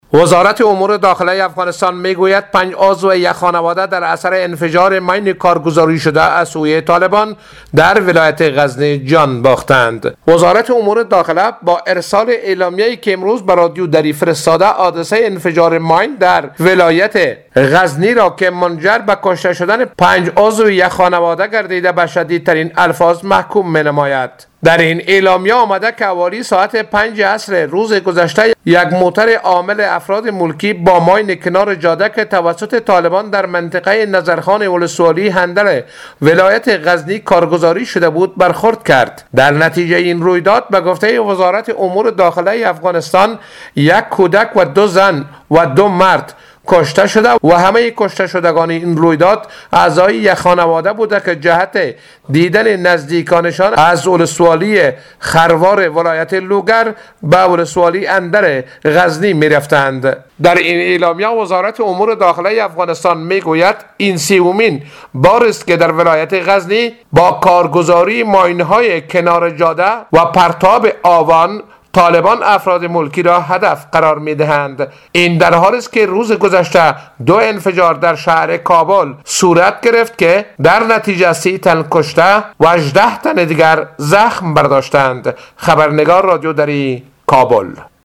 گزارش خبرنگار رادیودری: